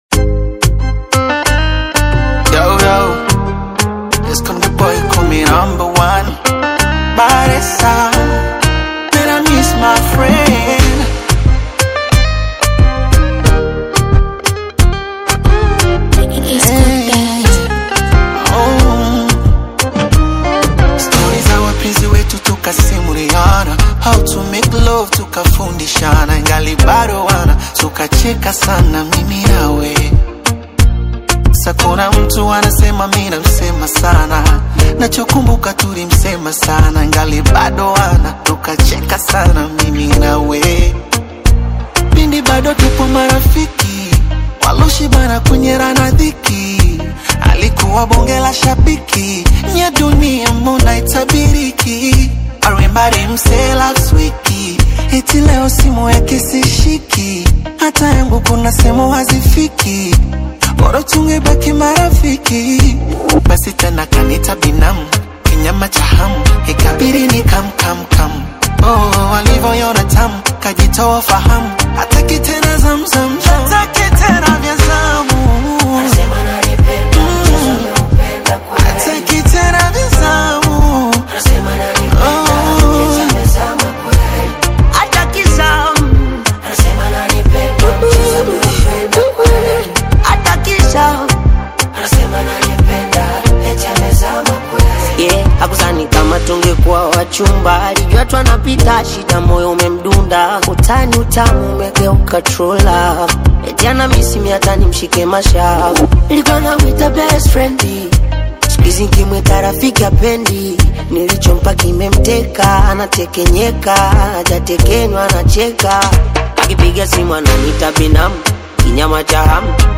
Popular Tanzanian urban music